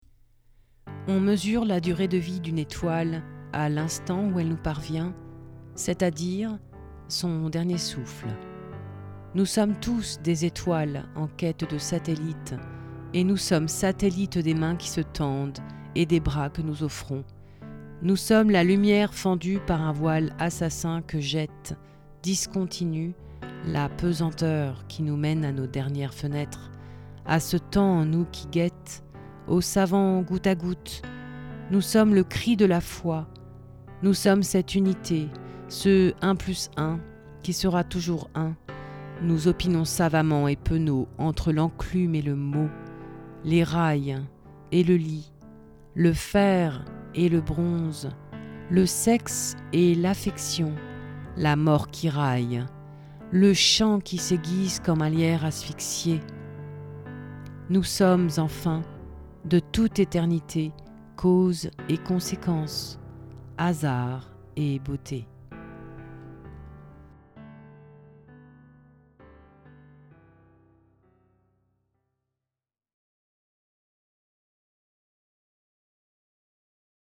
Texte et motif musical piano